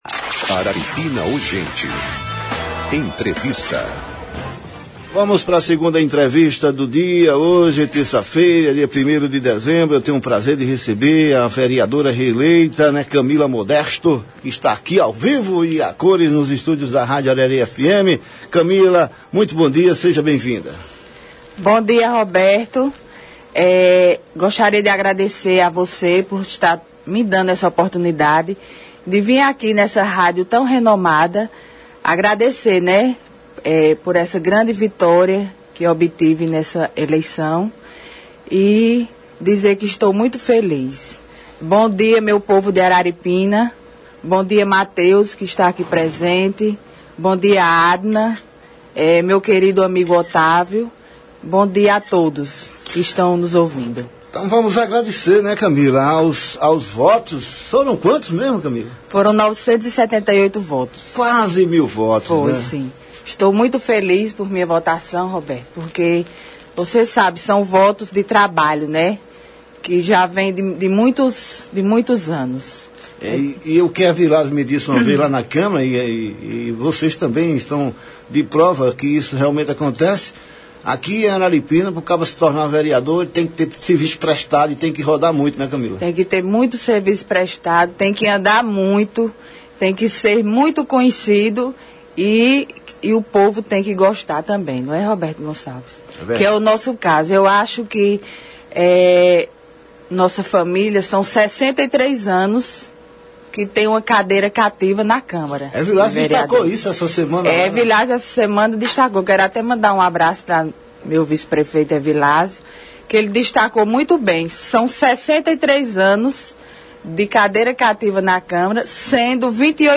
Em entrevista de agradecimento na manhã desta terça-feira (01), na Rádio Arari FM, a vereadora reeleita por três mandatos consecutivos, Camila Modesto, destacou alguns dos seus projetos apresentados nos últimos quatro anos na Câmara de Vereadores, entre eles, o Canal da Cohab, o calçamento do bairro Nova Roma, e a implementação de uma Casa de Parto Normal no município. A parlamentar também falou sobre a tradição política da sua família em Araripina.
Durante a entrevista, a ex-vereadora Darticlea Modesto, participou por telefone, e destacou a parceria política da sua filha, Camila, com o prefeito reeleito de Araripina Raimundo Pimentel.